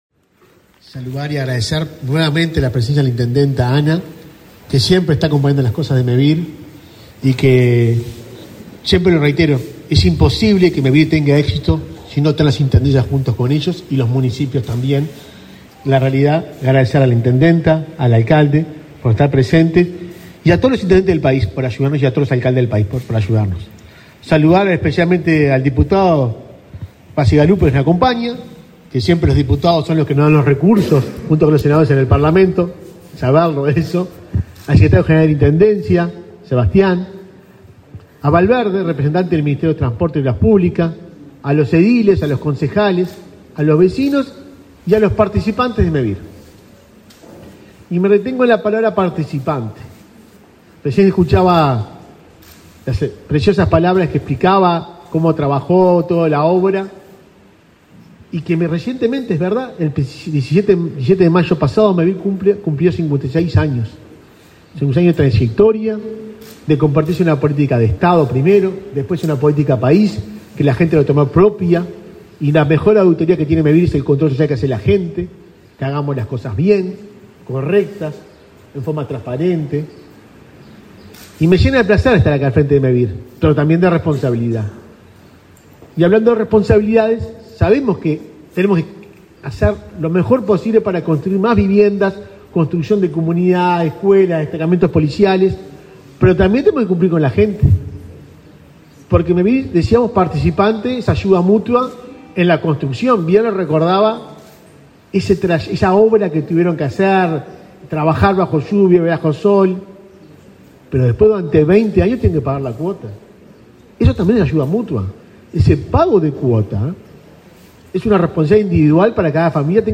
Palabras del presidente de Mevir, Juan Pablo Delgado
Palabras del presidente de Mevir, Juan Pablo Delgado 26/05/2023 Compartir Facebook X Copiar enlace WhatsApp LinkedIn Mevir realizó la escritura de 40 viviendas colectivas en Ecilda Paullier, en el departamento de San José, este 26 de mayo. En el acto participó el presidente de la institución, Juan Pablo Delgado.